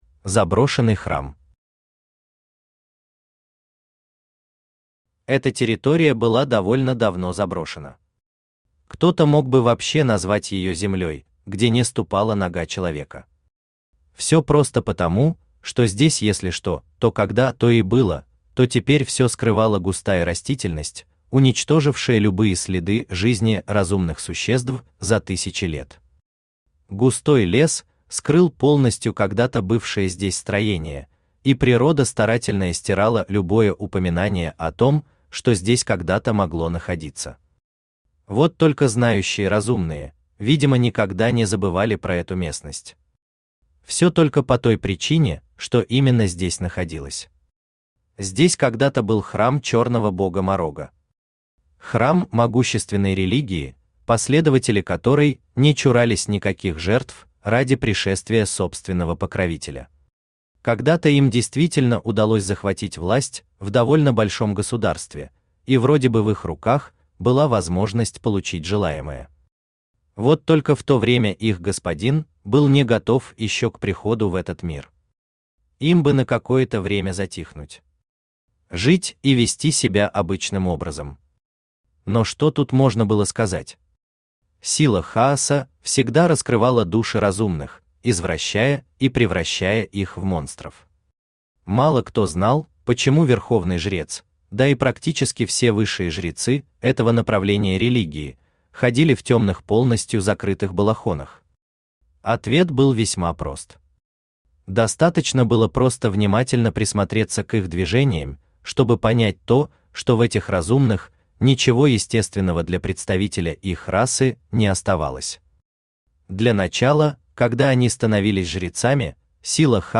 Аудиокнига Лесовик. Становление | Библиотека аудиокниг
Становление Автор Хайдарали Усманов Читает аудиокнигу Авточтец ЛитРес.